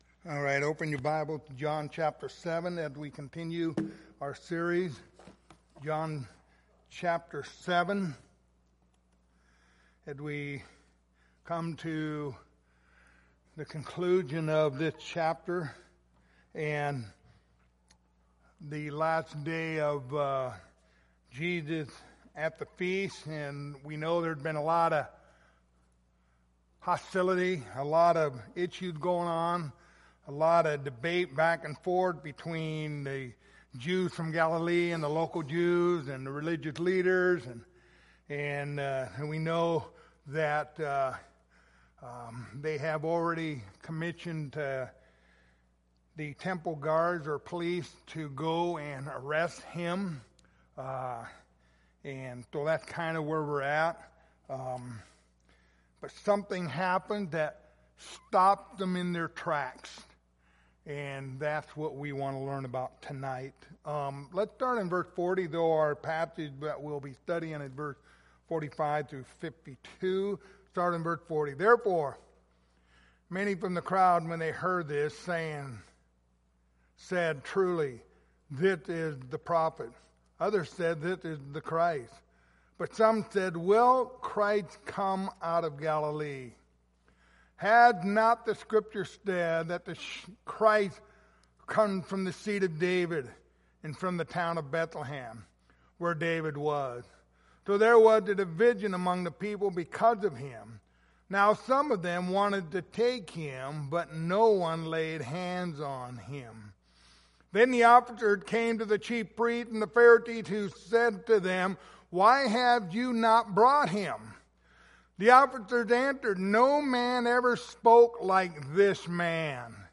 Passage: John 7:45-52 Service Type: Wednesday Evening